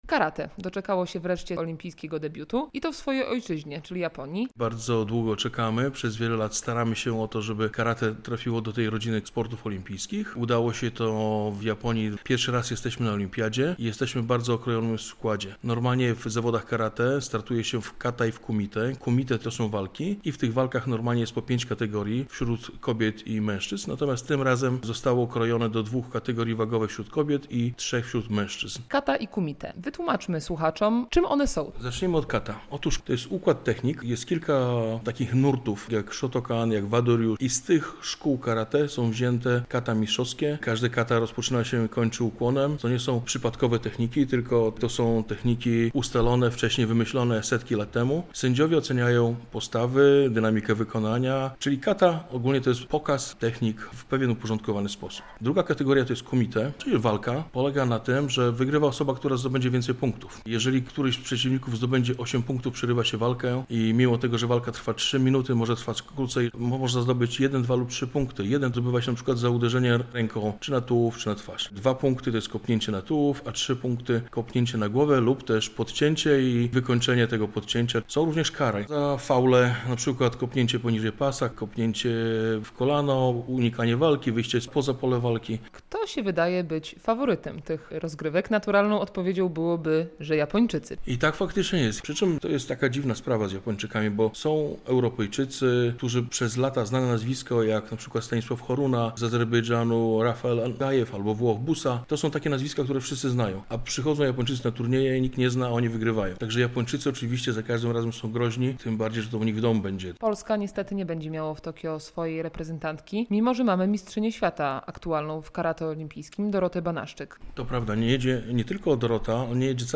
Karate.